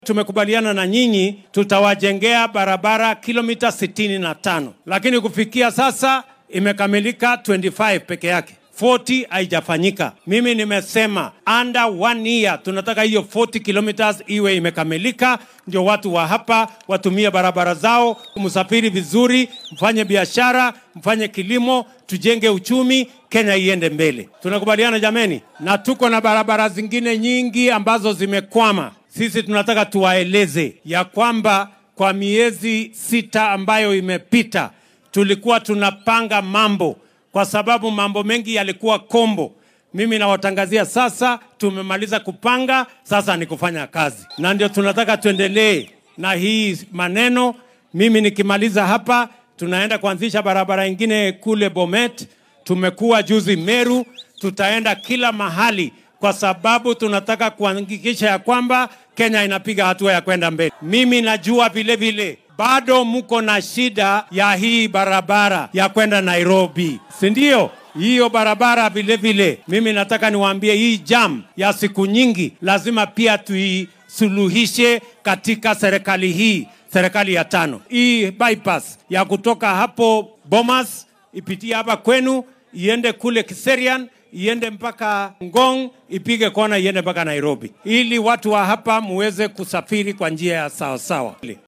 Waxaa uu dadweynaha kula hadlay degmada Ongata Rongai ee dowlad deegaankaasi.